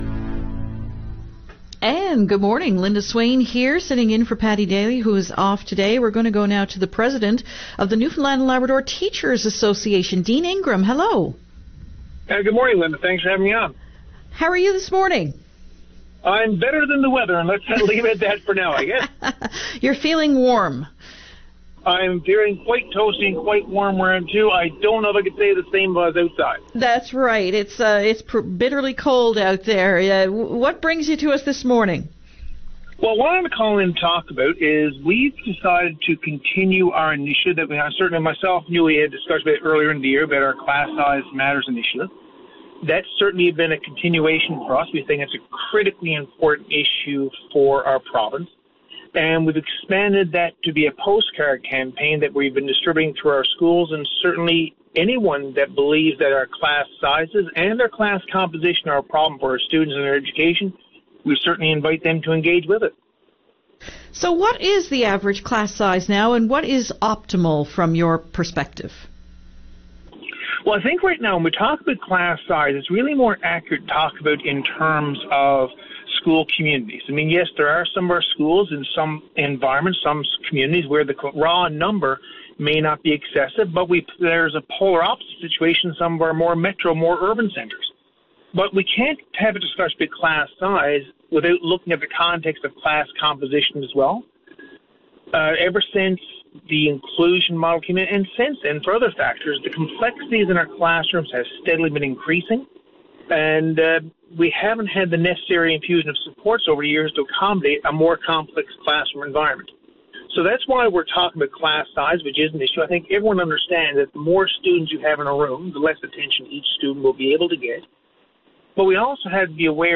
Media Interview - VOCM Open Line Feb 21, 2020